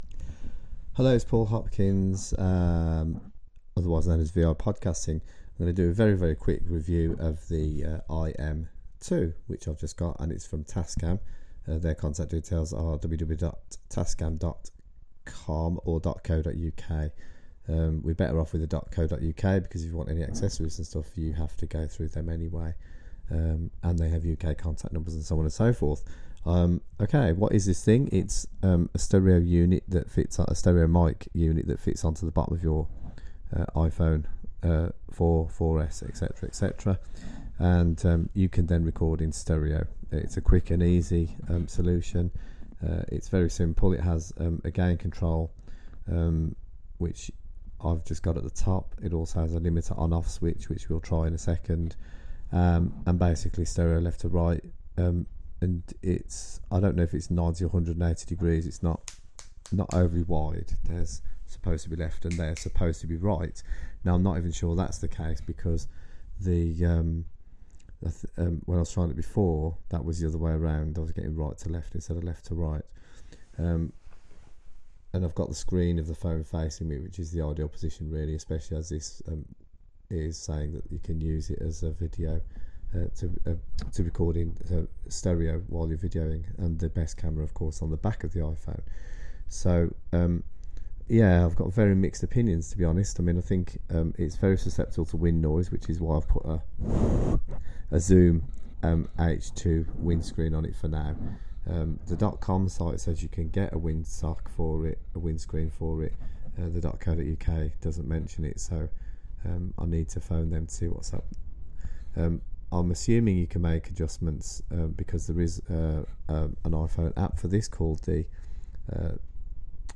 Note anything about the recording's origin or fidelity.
Semi organised review of the Tascam IM2. Mine may be faulty as you will hear so, this review migh be for the audio heads rather than anyone expecting perfect recording and comment.